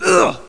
ouch04.mp3